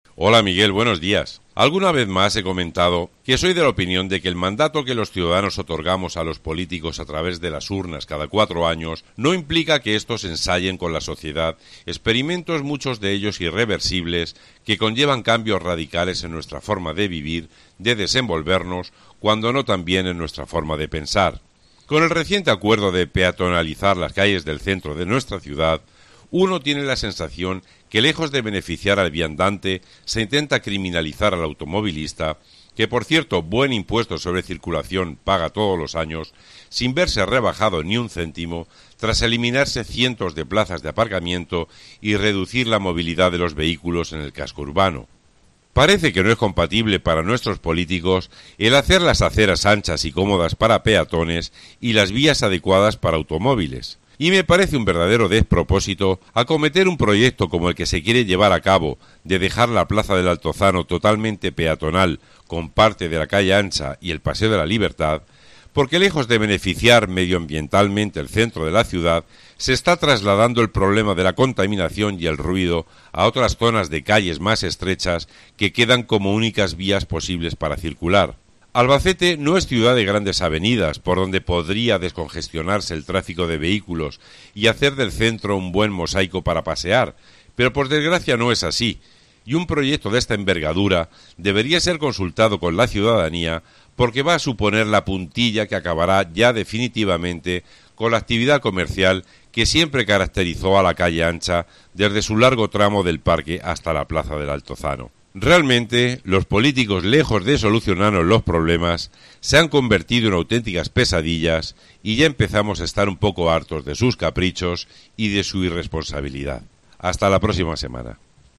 OPINIÓN